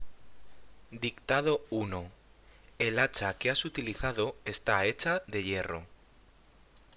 Dictado 1